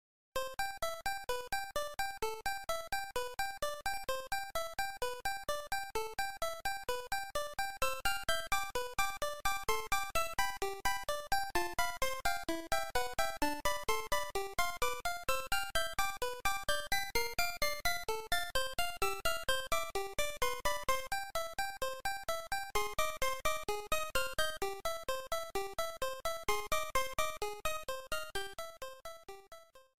sound effect